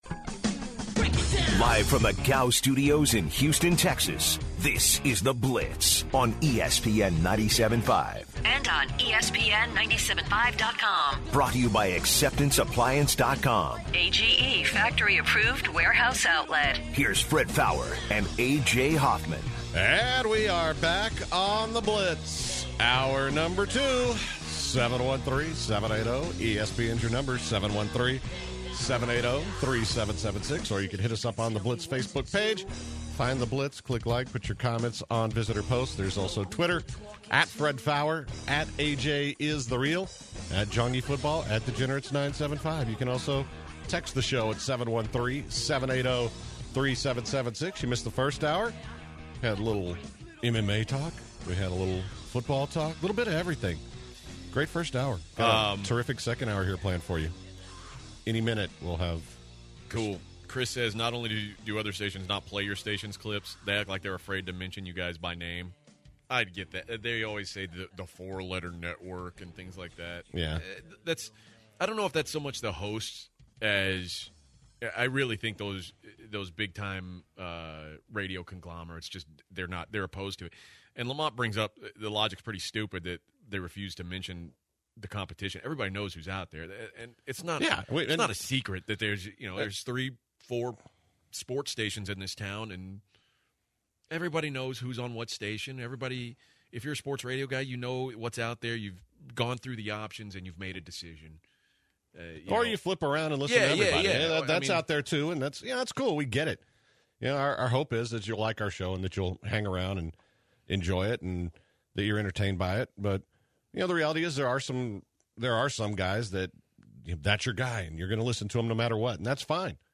The hour started off with the guys interviewing UH head coach Tom Herman. The guys then talked about NFL teams moving to different cities and ended the hour with the Gem of the Day.